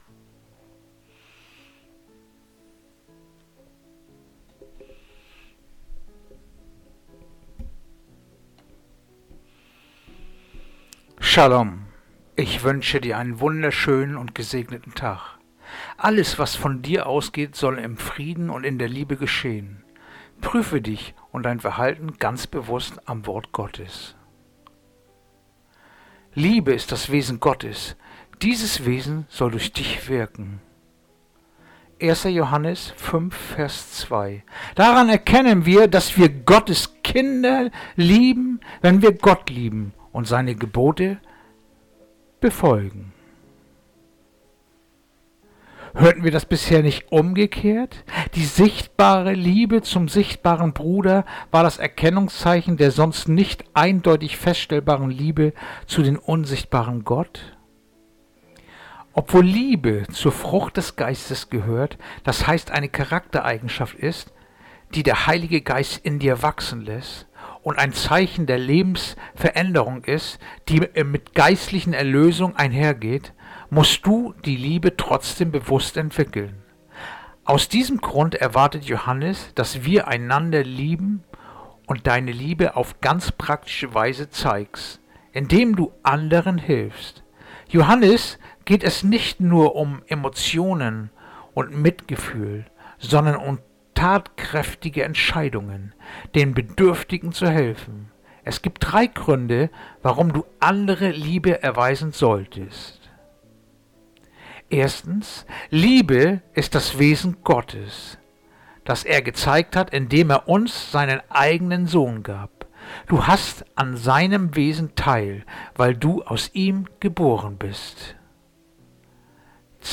Andacht-vom-26-Mail-1-Johanes-5-2.mp3